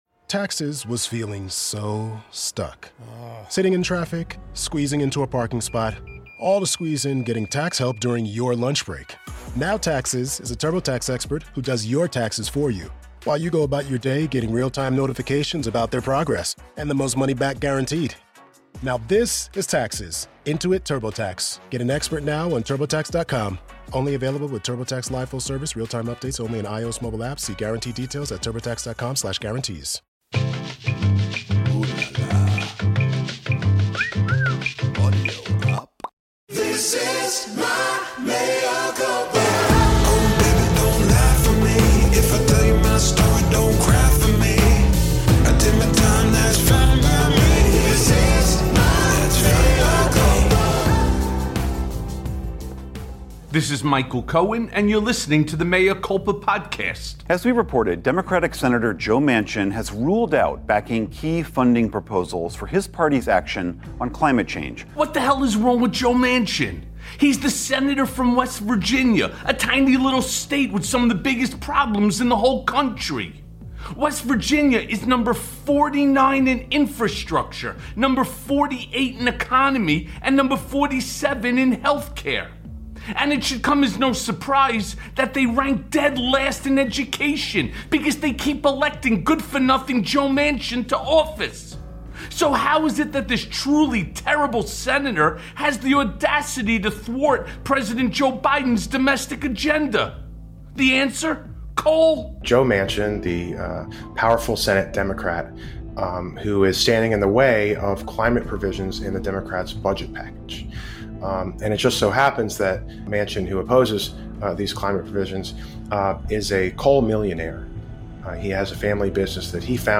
Manchin Burns it All Down Again + A Conversation with Joe Trippi